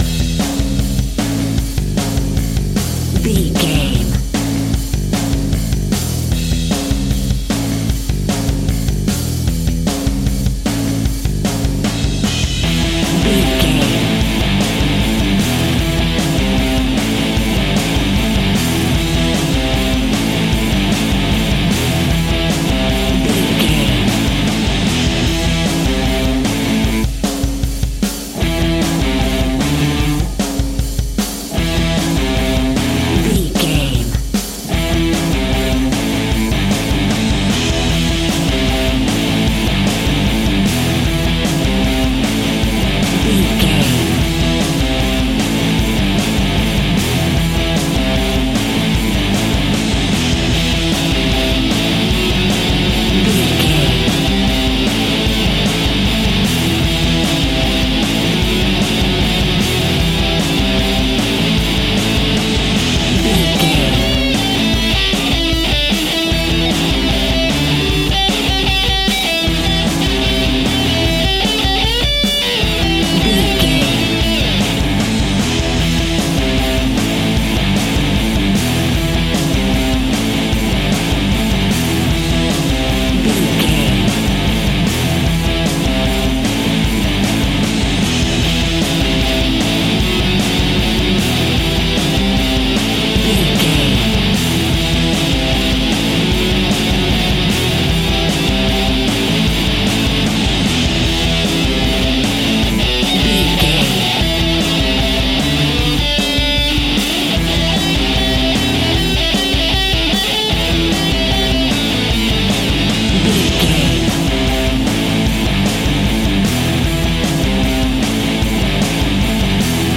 Hard Heavy Rock.
Ionian/Major
indie pop
indie rock
punk
pop rock
drums
bass guitar
electric guitar
piano
hammond organ